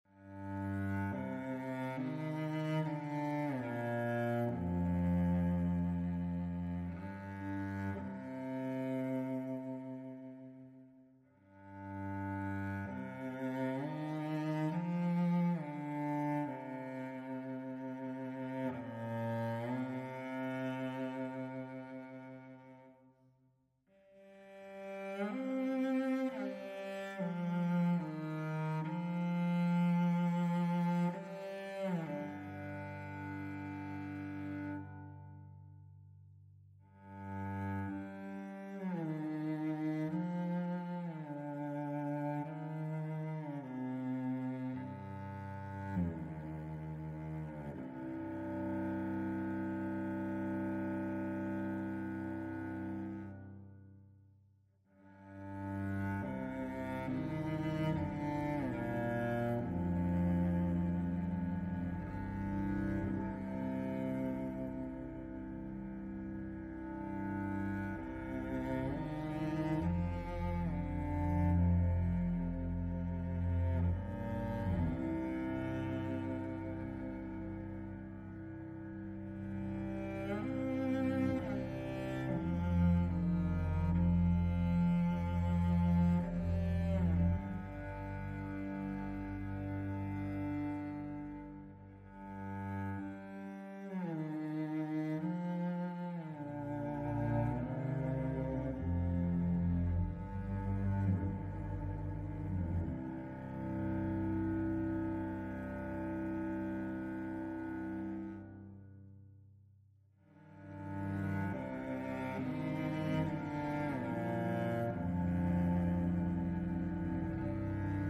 cello.mp3
KEphXNeWABx_cello.mp3